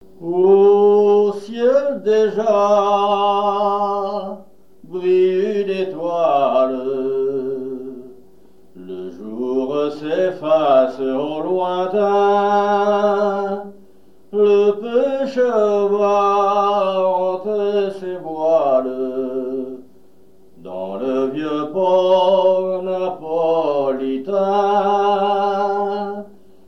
Genre strophique
répertoire de chansons
Pièce musicale inédite